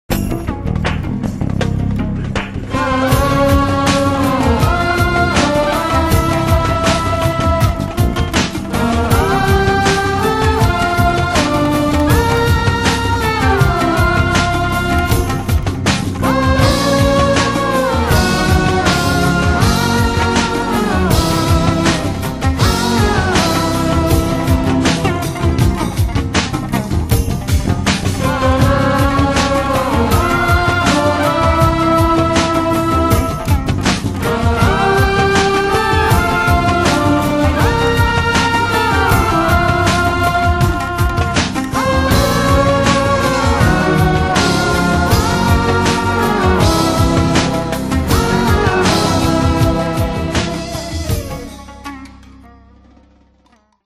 progr. Keyb. Perc. Bass/Guitar
Sax Soprano
Vocal
Guitar
Keyboards - el. piano
Percussion